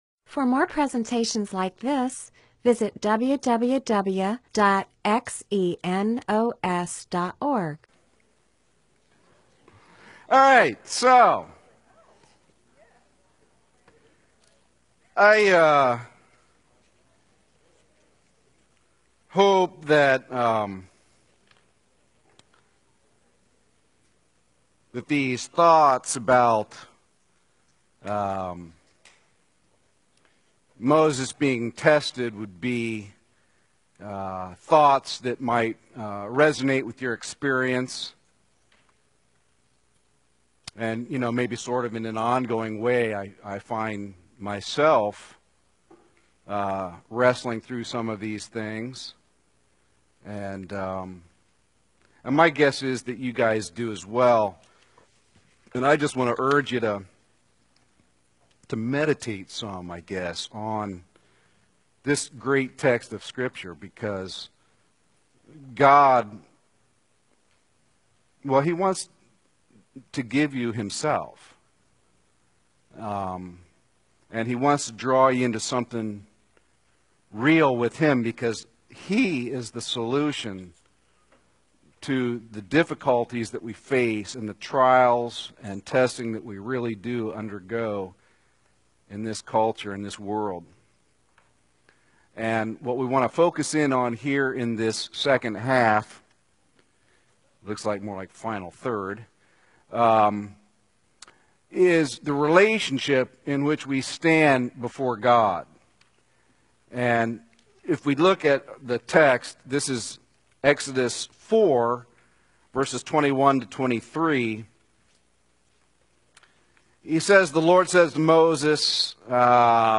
Bible teaching (presentation, sermon) on Exodus 4:21-23